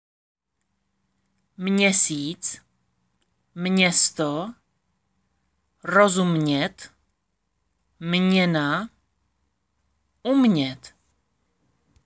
Tady si můžete stáhnout audio na výslovnost MĚ.